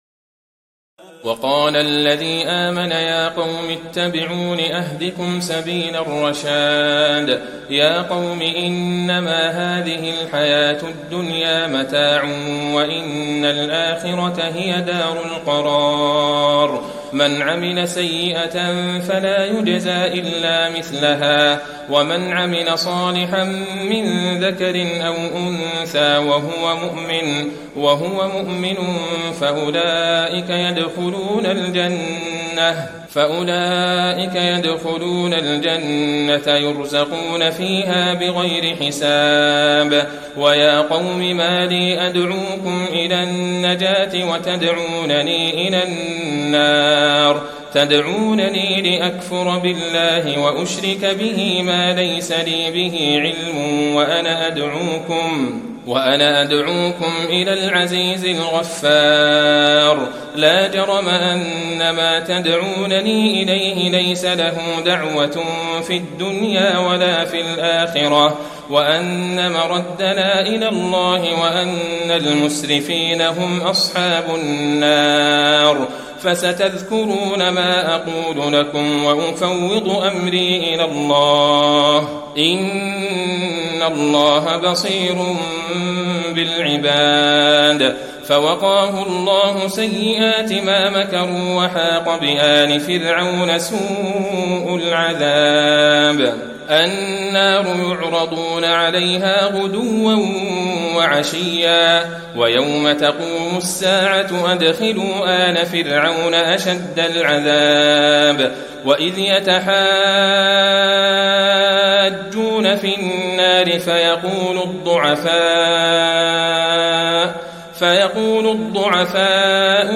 تراويح ليلة 23 رمضان 1435هـ من سور غافر (38-85) وفصلت (1-45) Taraweeh 23 st night Ramadan 1435H from Surah Ghaafir and Fussilat > تراويح الحرم النبوي عام 1435 🕌 > التراويح - تلاوات الحرمين